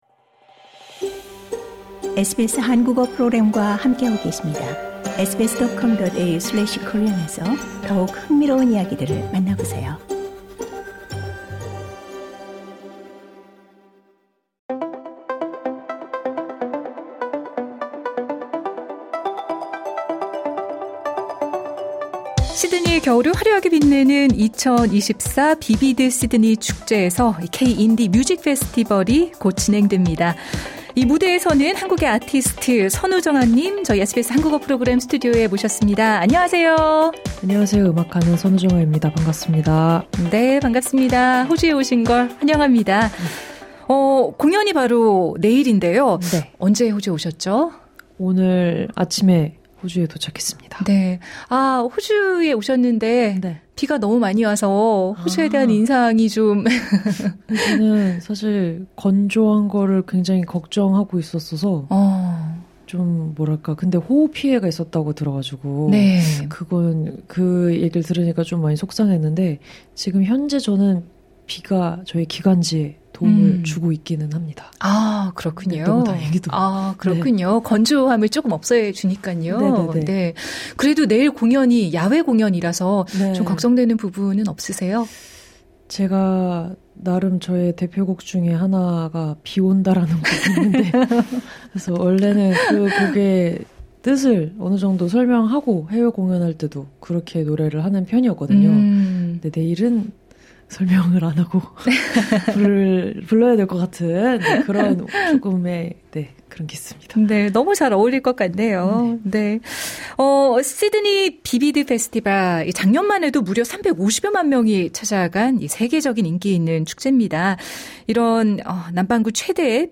비비드 시드니 K-인디 뮤직 페스티벌에 참가하는 한국의 보컬리스트 선우정아 님은 공연에 앞서 SBS 한국어 프로그램 스튜디오에서 음악에 대한 이야기를 나눴다.